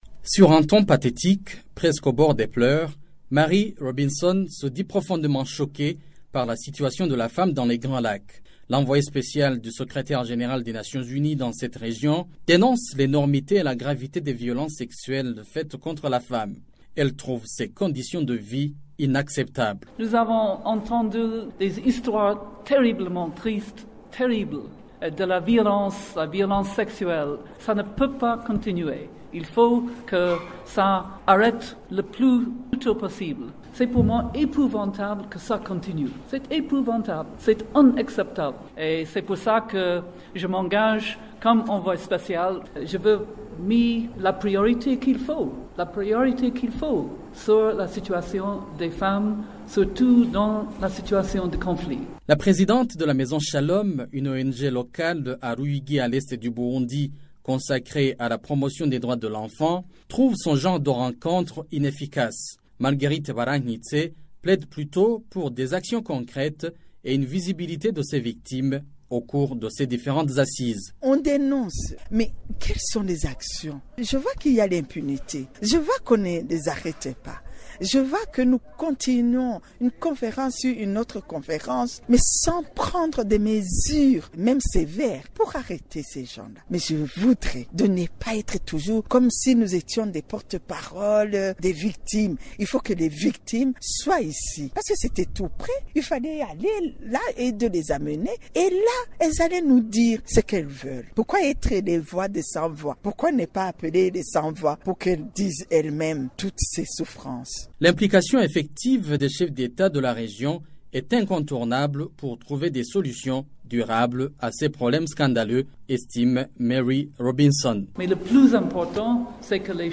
구어체 프랑스어 (아프리카)